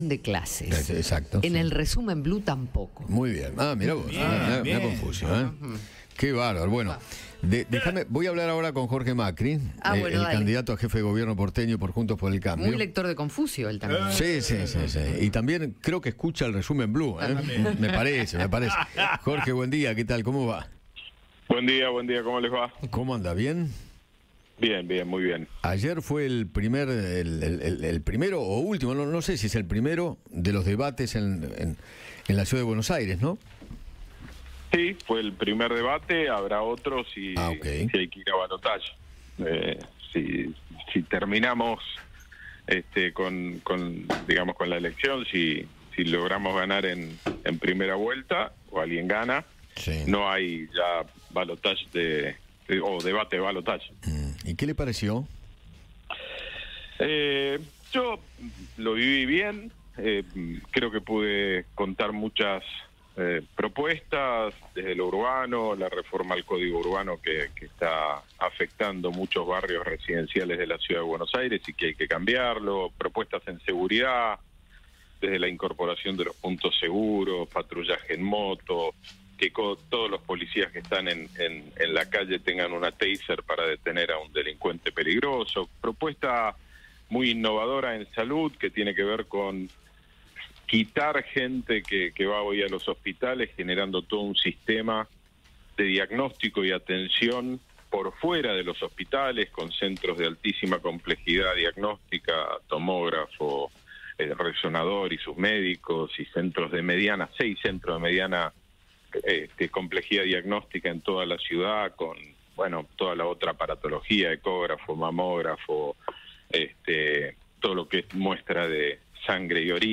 Jorge Macri, candidato a jefe de Gobierno porteño por Juntos por el Cambio, conversó con Eduardo Feinmann sobre su participación en el debate con el resto de los candidatos.